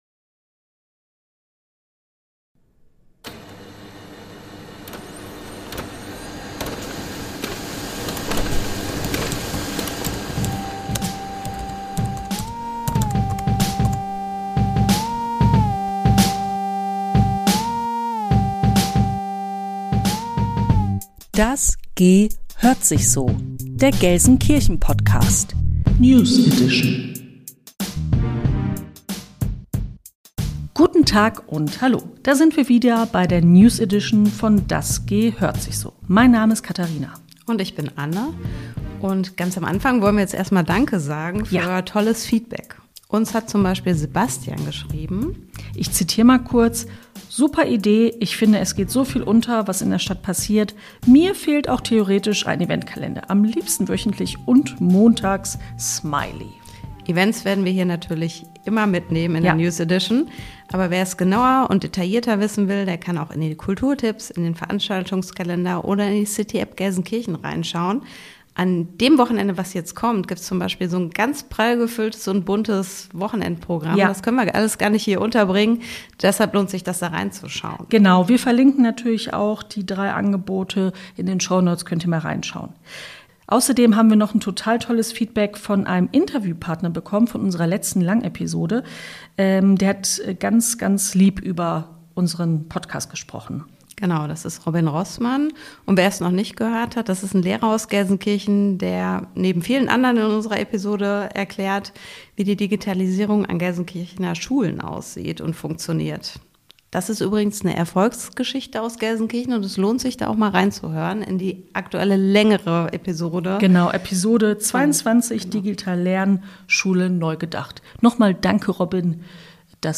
In rund zehn Minuten liefert unser neues Podcast-Format aktuelle Meldungen, Veranstaltungstipps und Infos aus dem Stadtgeschehen – kompakt, unterhaltsam und mit einem Augenzwinkern.